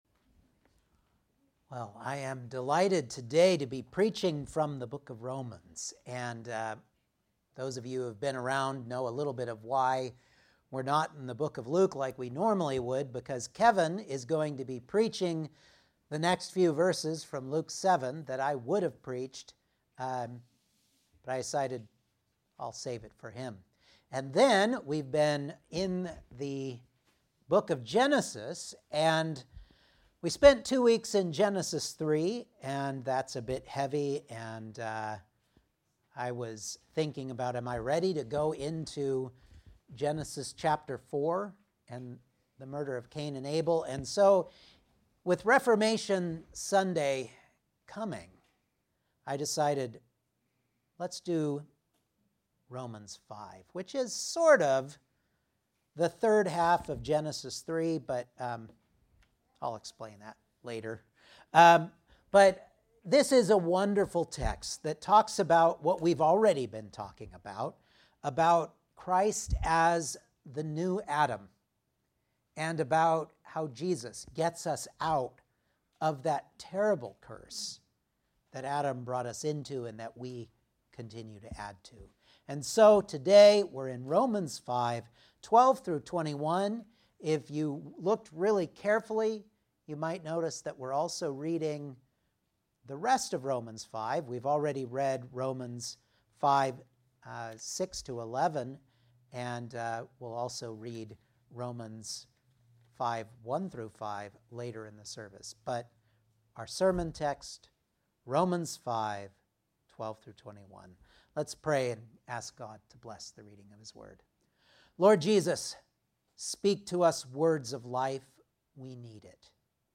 Romans 5:12-21 Service Type: Sunday Morning Outline